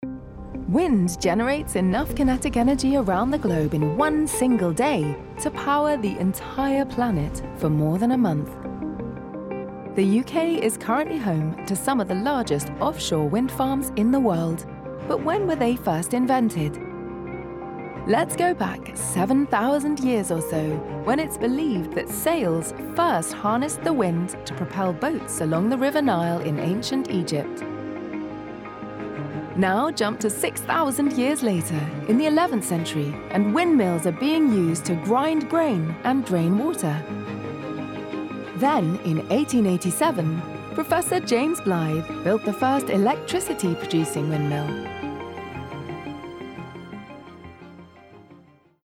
Engels (Brits)
Commercieel, Natuurlijk, Veelzijdig, Diep, Warm
Explainer